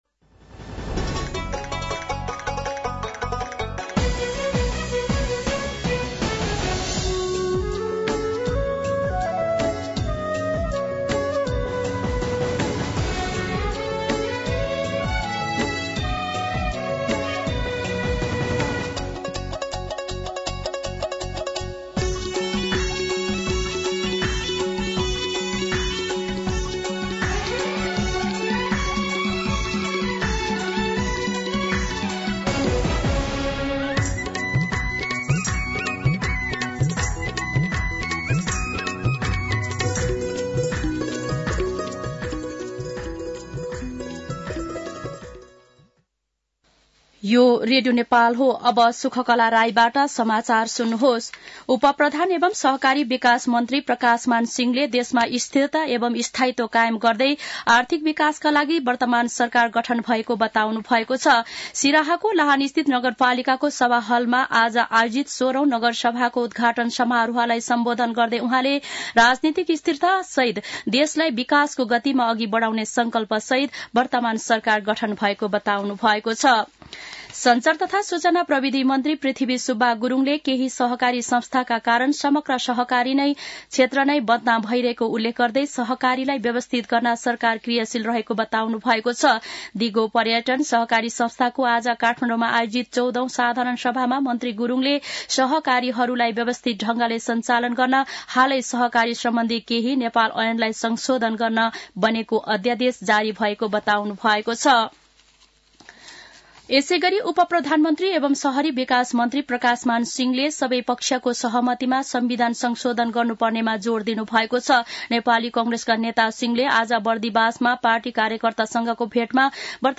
दिउँसो ४ बजेको नेपाली समाचार : २१ पुष , २०८१
4-pm-Nepali-News.mp3